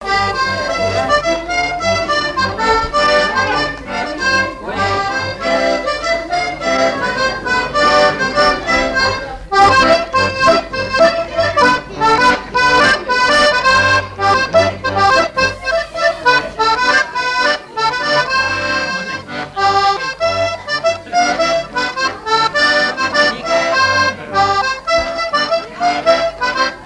Chants brefs - A danser
danse : gigouillette
Veillée de collectage de chants et de danses
Pièce musicale inédite